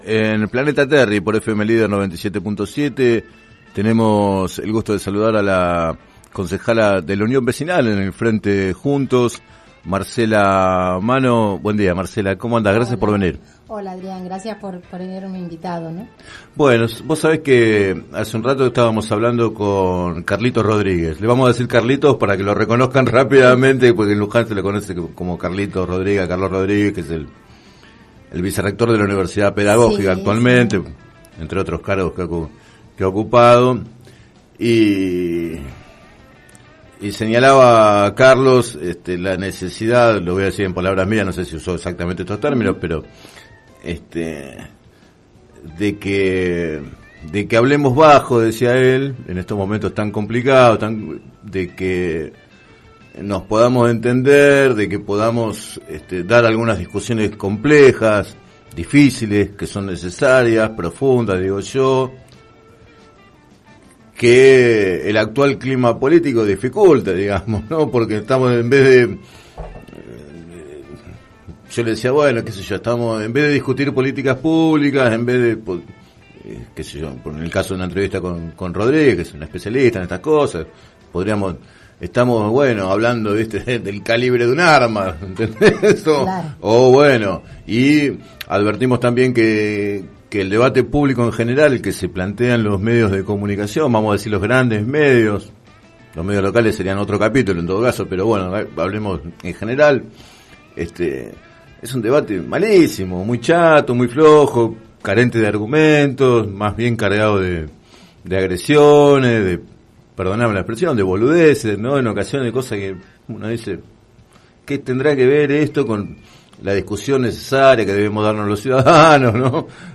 En declaraciones al programa Planeta Terri de FM Líder 97.7, la concejala Marcela Manno, de la Unión Vecinal en Juntos, integrante del colectivo de familiares, informó sobre la situación y destacó la necesidad de una pronta solución al problema.